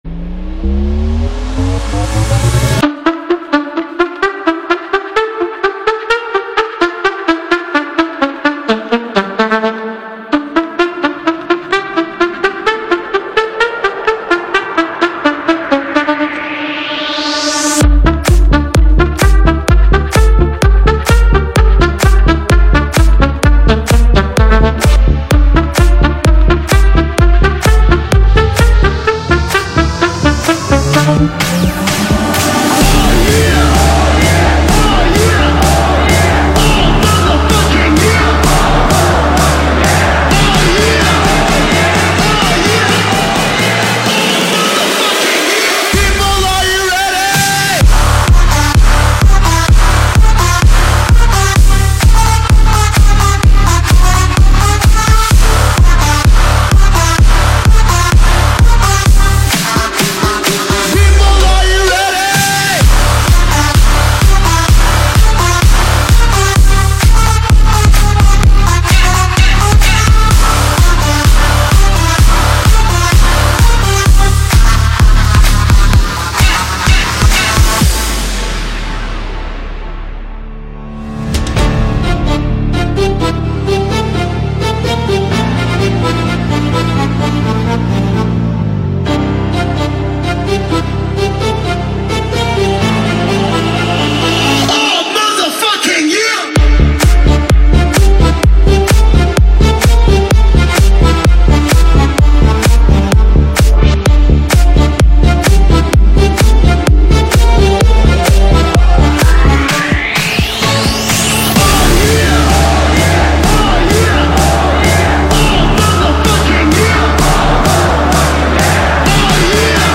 劲爆DJ、比较嗨的DJ